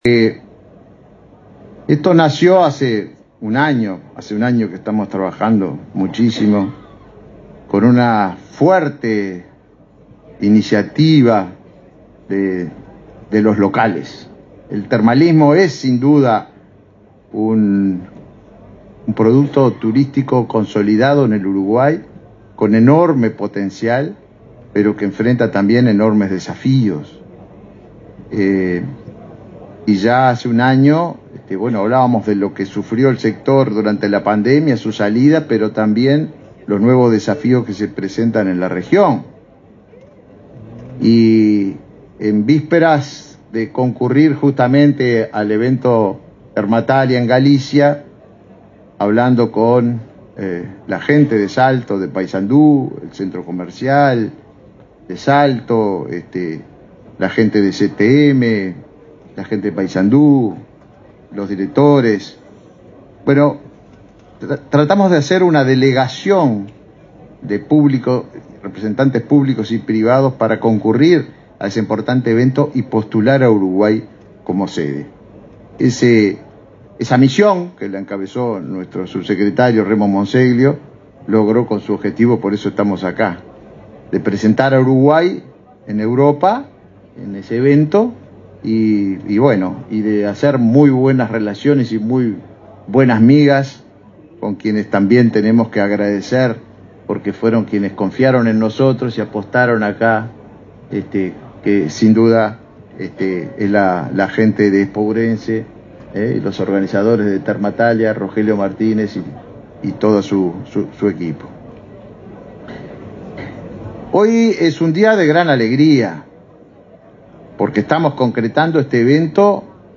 Palabras del ministro de Turismo, Tabaré Viera
Palabras del ministro de Turismo, Tabaré Viera 04/10/2023 Compartir Facebook X Copiar enlace WhatsApp LinkedIn Este miércoles 4 en Salto, el ministro de Turismo, Tabaré Viera, participó en la apertura del 21.° Encuentro Internacional del Turismo Termal, Salud y Bienestar, Termatalia.